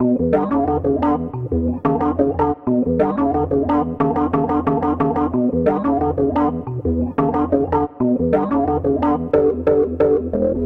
另一个采样的罗德斯钢琴
描述：适用于嘻哈节拍
Tag: 90 bpm Hip Hop Loops Piano Loops 1.80 MB wav Key : E